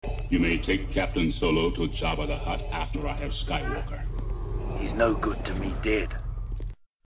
Cytaty z Imperium Kontratakuje są w dwóch wersjach, JW to Jason Wingreen, czyli głos "Stary", a TM to Temuera Morrison, czyli głos "Nowy".